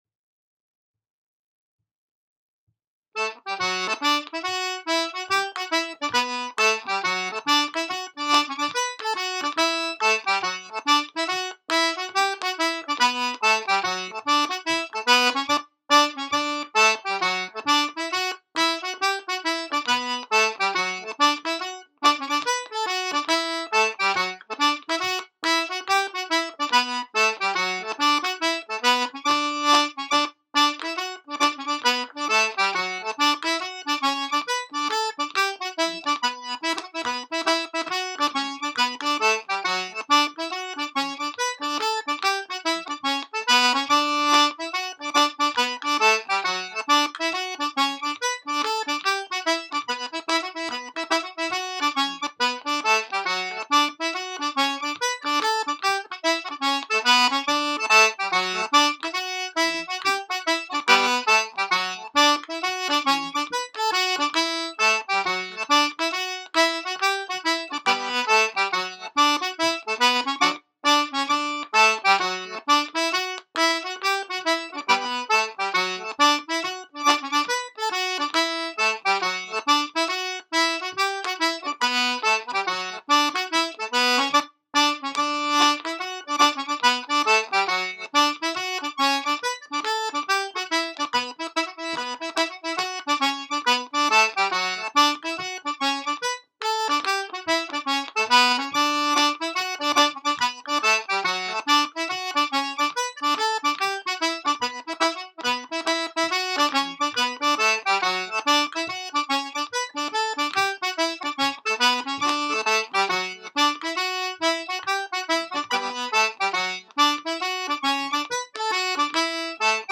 The Quilty (70 bpm
Set Dance
The-Quilty-70-bpm.mp3